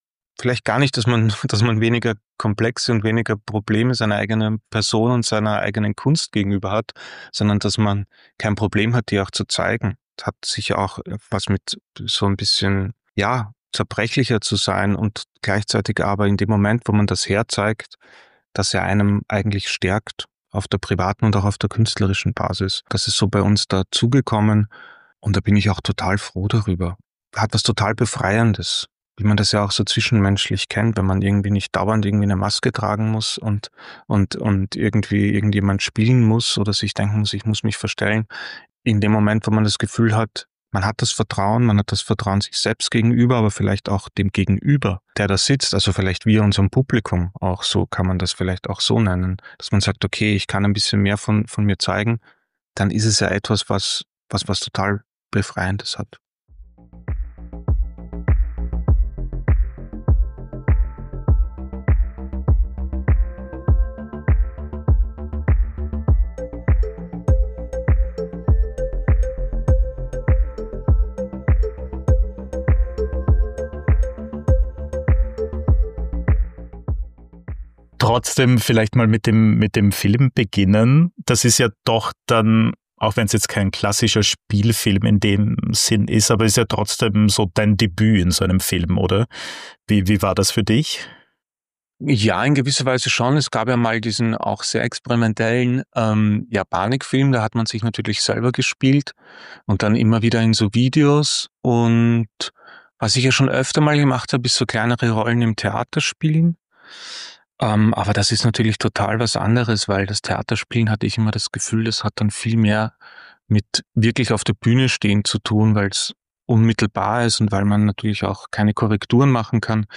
Macht, Geld, Gier und ein Casino als Parabel auf den Kapitalismus: Ich spreche mit dem Musiker Andreas Spechtl (Ja, Panik) über seinen Auftritt im Spielfilm “Un Gran Casino” von Filmemacher Daniel Hoesl.